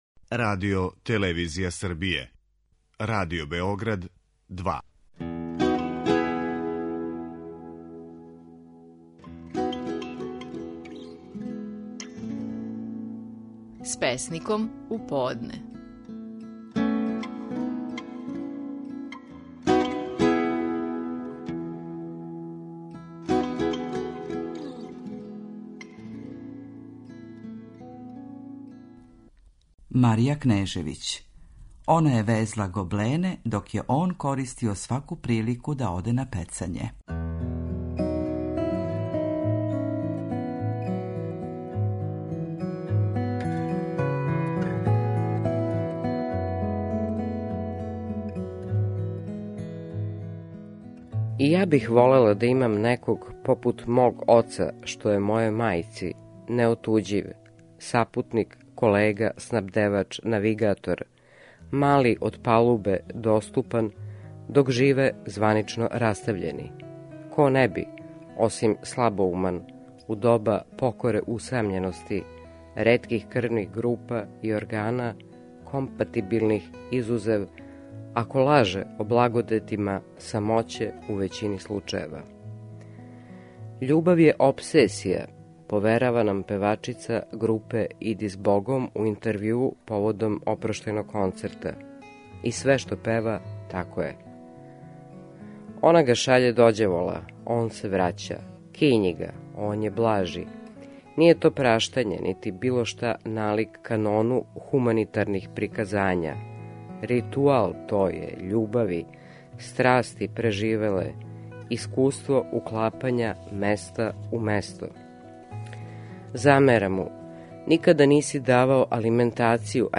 Наши најпознатији песници говоре своје стихове